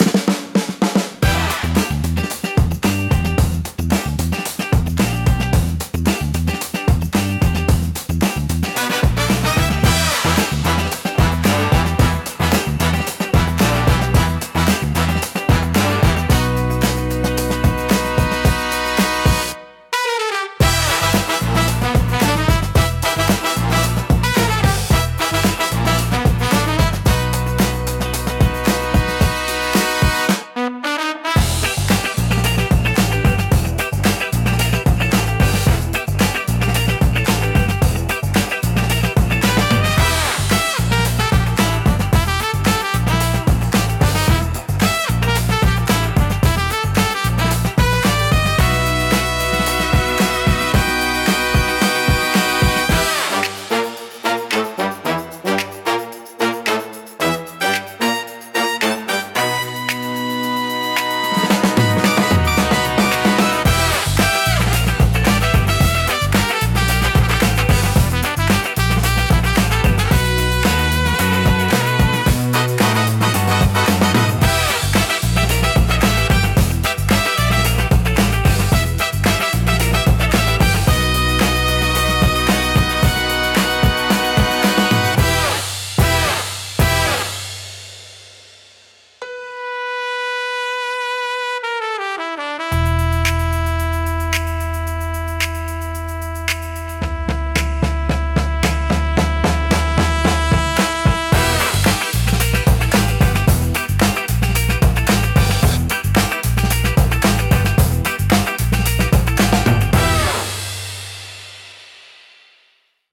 不穏さとエネルギーが同居し、聴く人の集中力を高めつつドキドキ感を作り出します。